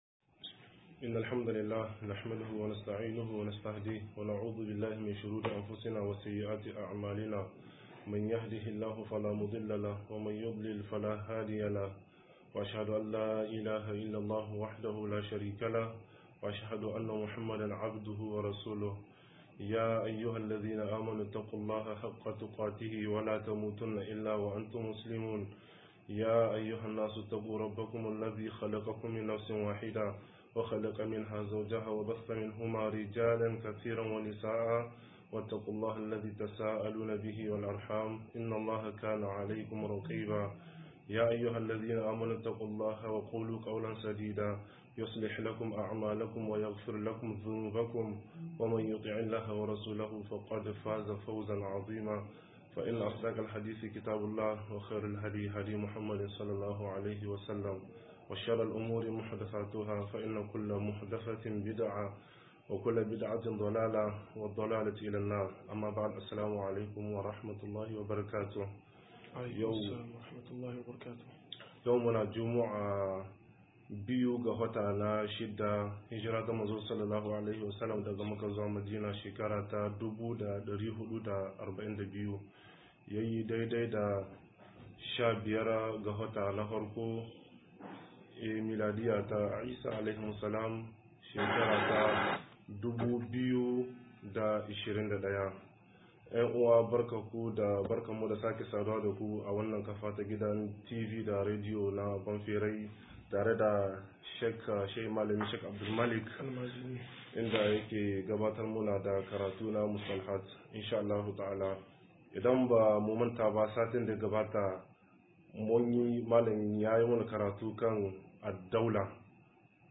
110-Juyin Mulki - MUHADARA